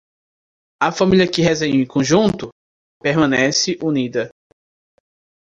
Uitgesproken als (IPA)
/kõˈʒũ.tu/